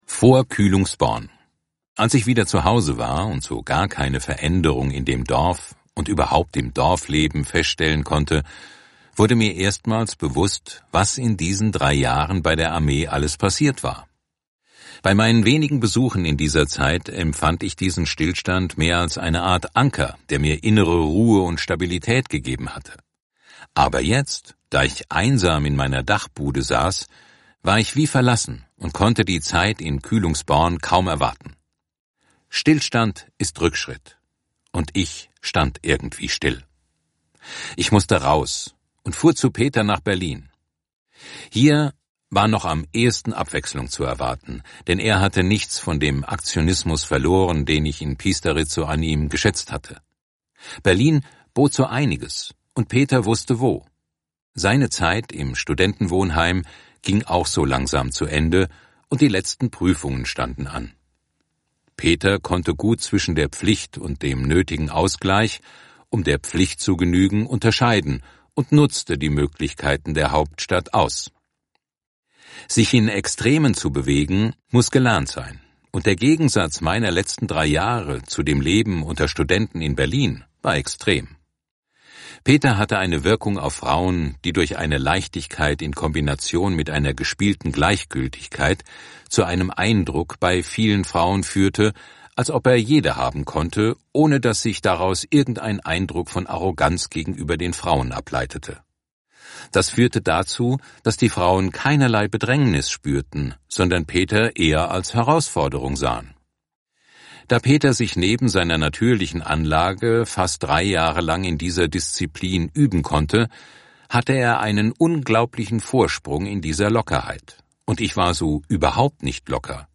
So ist das Hörbuch „Eine Ahnung von Leben“ genau die Erzählung, die einen tieferen Einblick für das Verstehen schafft, den wir Deutschen brauchen.